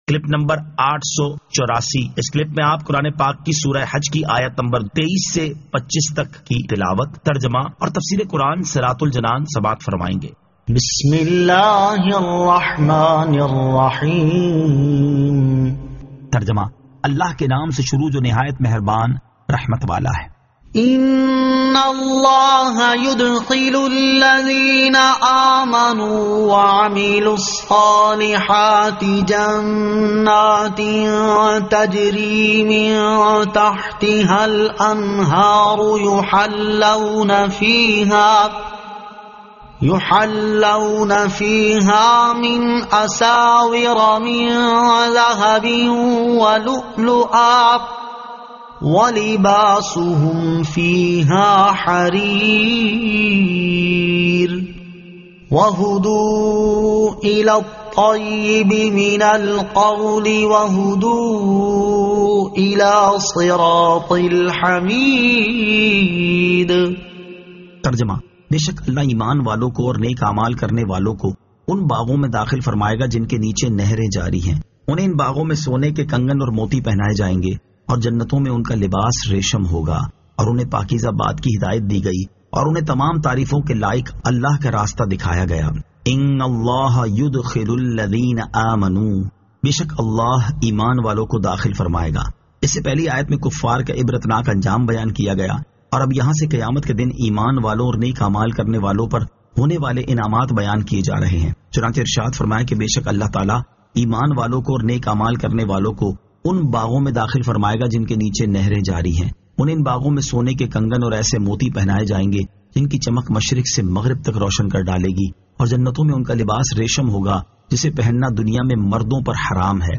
Surah Al-Hajj 23 To 25 Tilawat , Tarjama , Tafseer
2022 MP3 MP4 MP4 Share سُورَۃُ الْحَجِّ آیت 23 تا 25 تلاوت ، ترجمہ ، تفسیر ۔